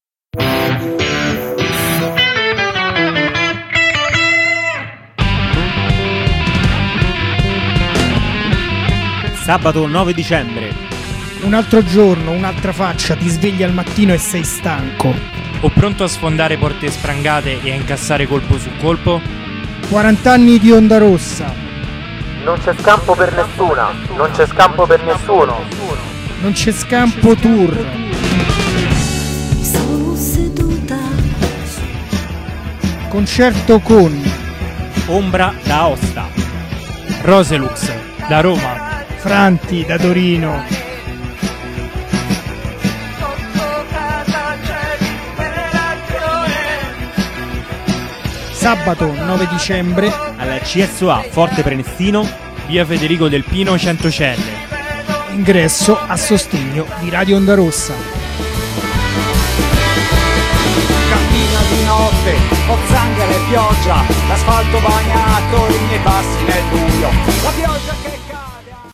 Spottino